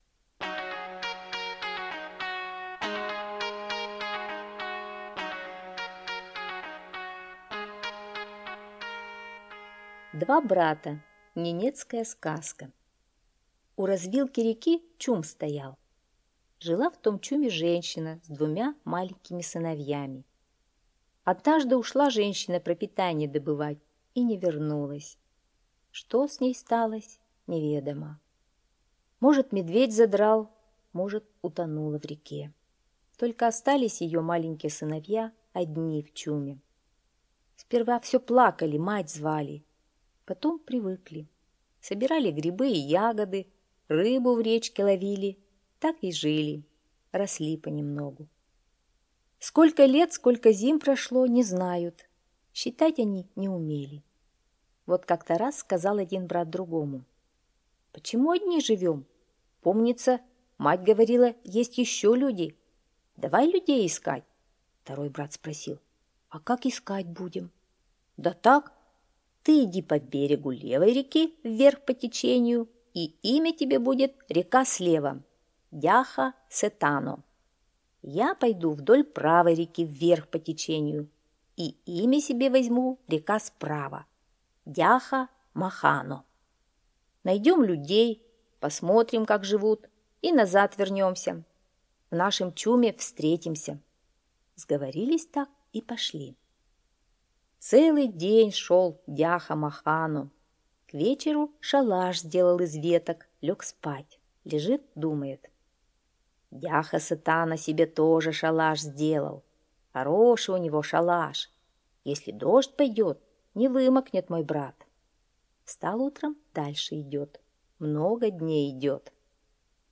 Два брата - ненецкая аудиосказка - слушать онлайн